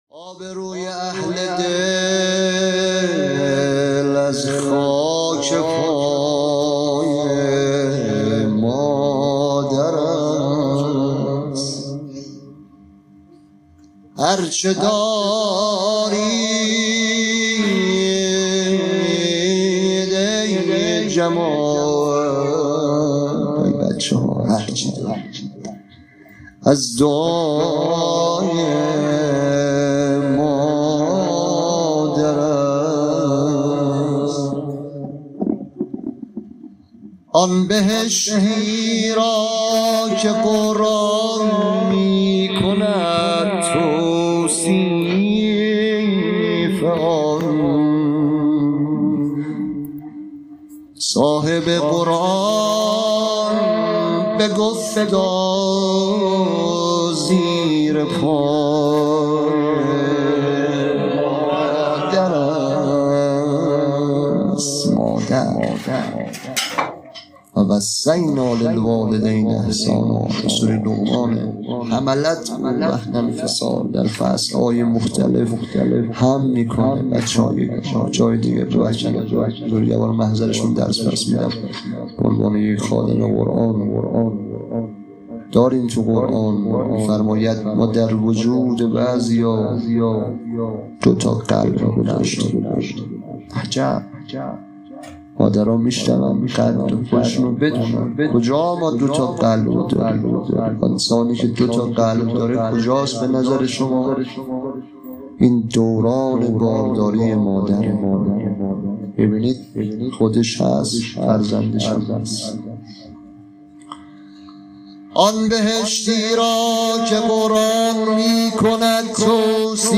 عزاداری